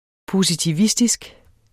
Udtale [ positiˈvisdisg ]